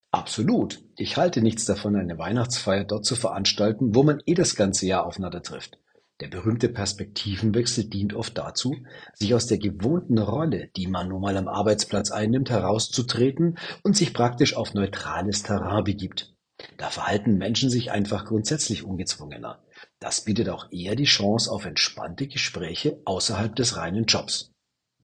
Er ist ehemaliger Leistungssportler, Mental- und Kommunikationscoach.